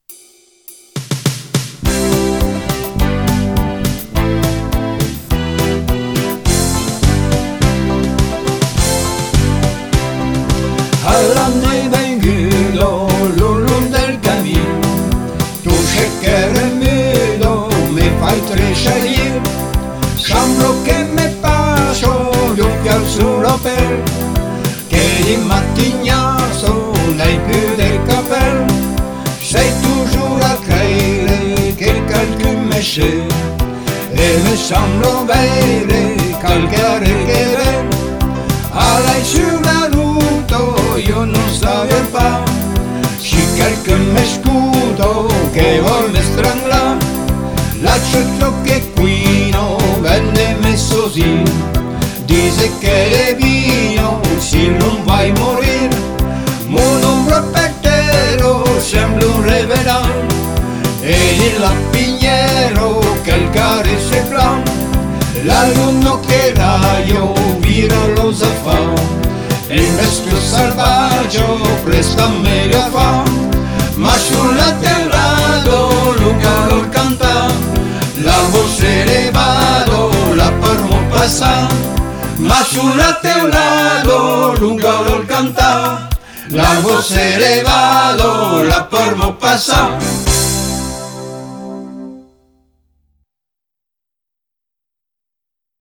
Ici dans sa version  Québec lors de la tournée